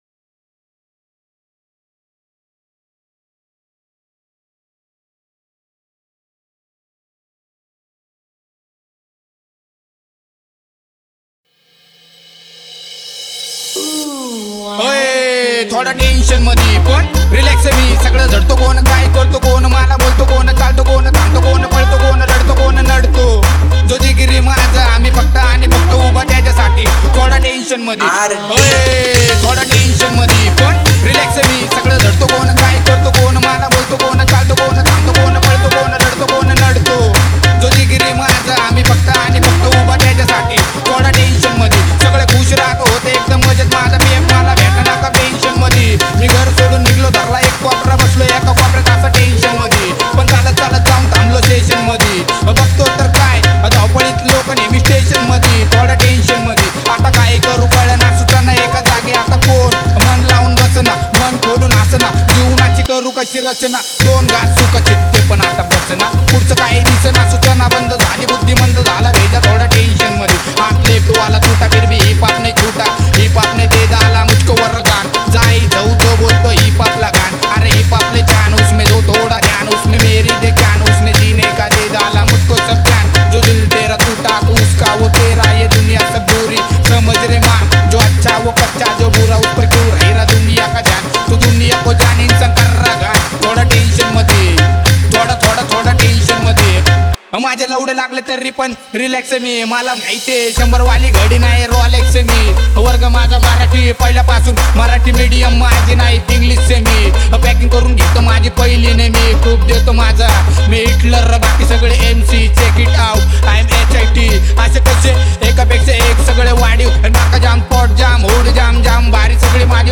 • Category: MARATHI SINGLE